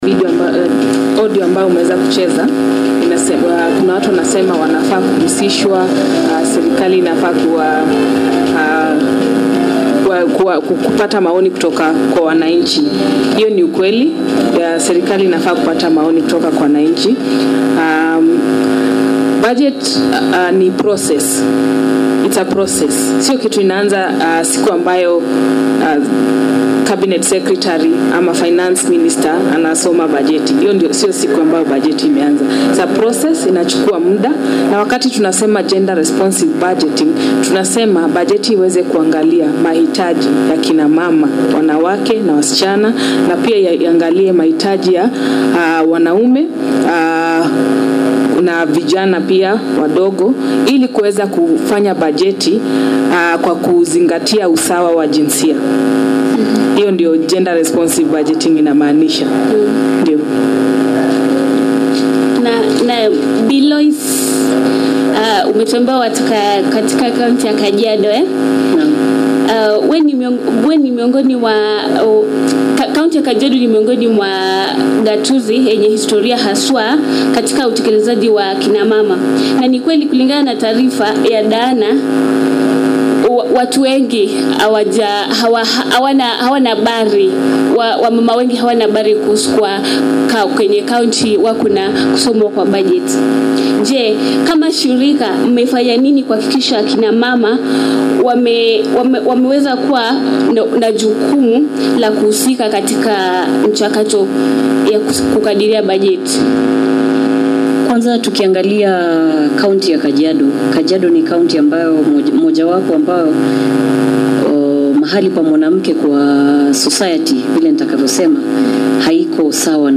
The talk show is produced by radio Domus Marie and is about the importance of public participation and it is part of ‘our county our responsibility’ project which was undertaken to empower the local population to actively participate in all democratic processes at the county level.